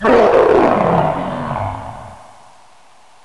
zombi_death_1.mp3